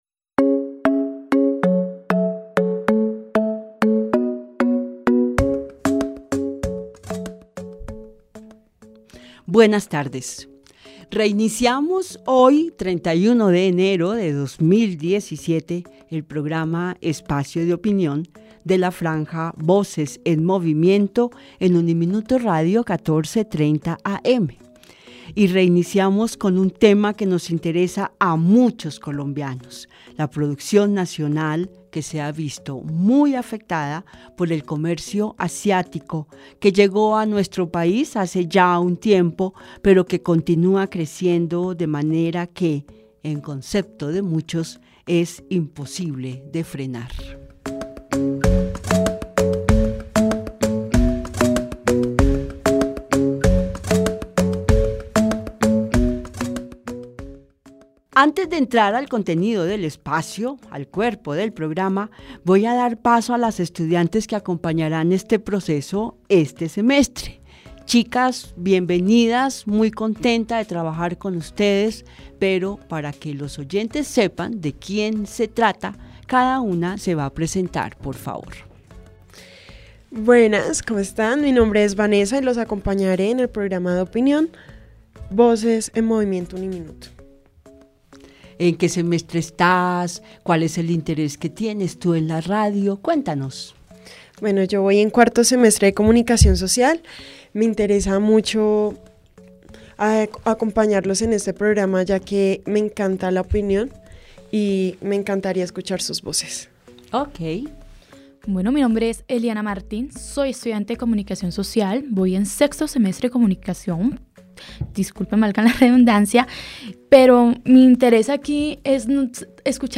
Espacio de Opinión habló con comerciantes de San Victorino en almacenes e islotes en los que prima la producción nacional.